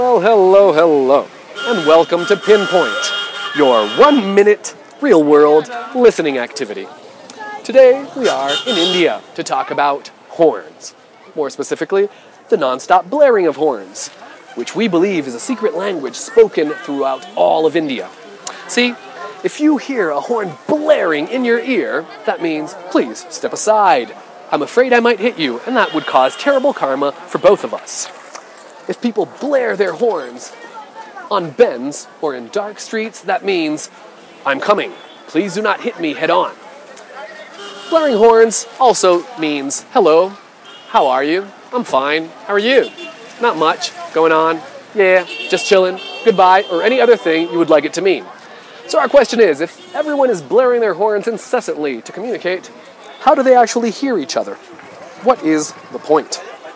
Pinpoint - honking of horns